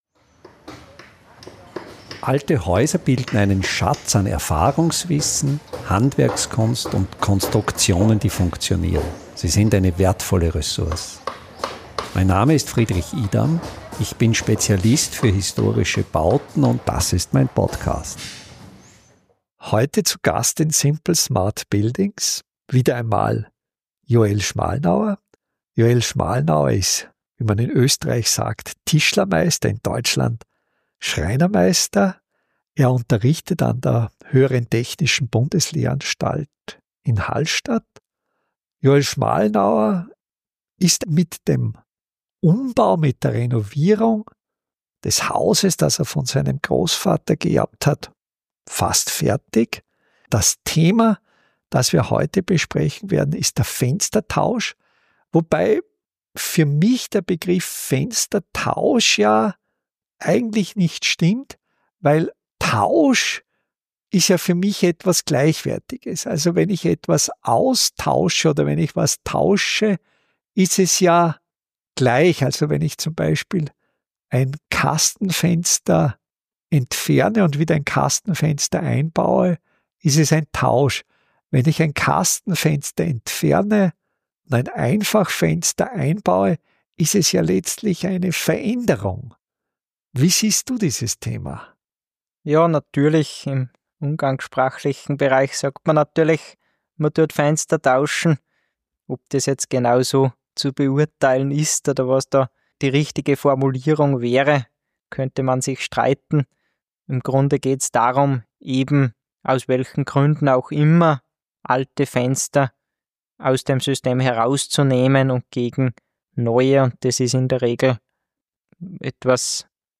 Gespräch über den Fenstertausch ~ Simple Smart Buildings Podcast